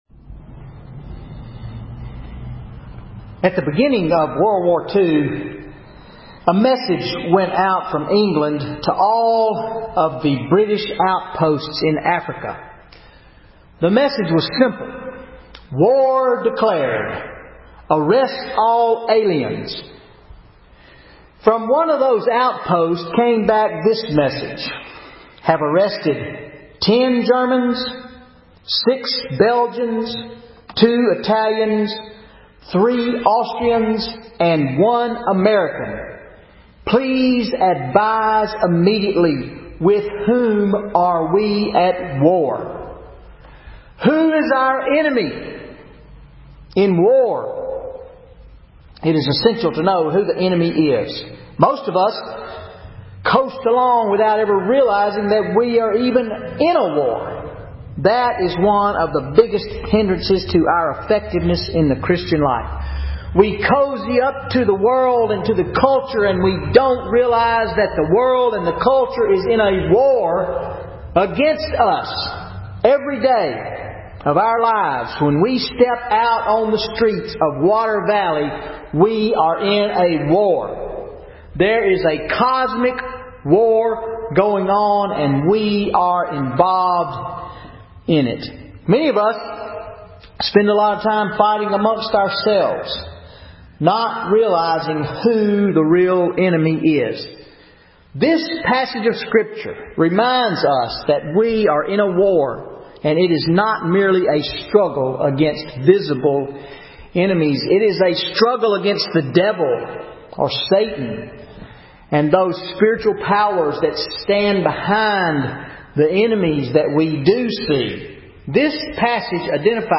0 Comments VN810147_converted Sermon Audio Previous post June 2